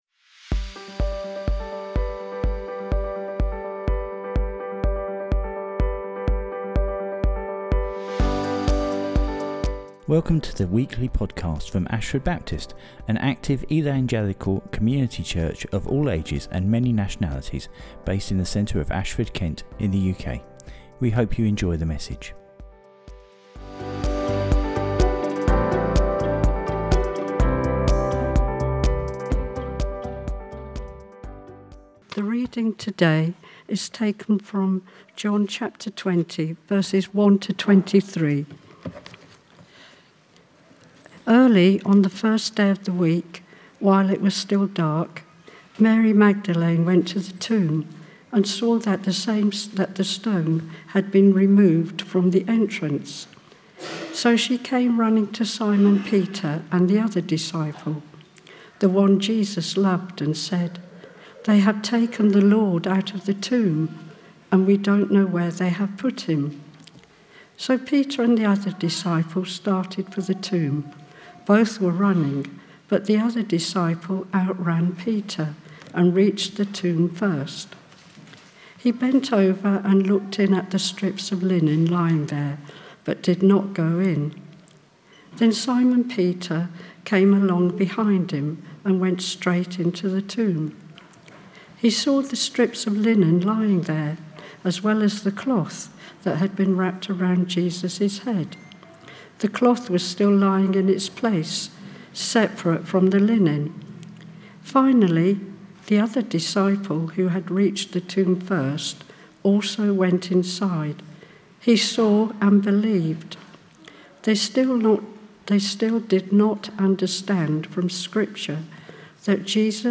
The bible readings are from John 20.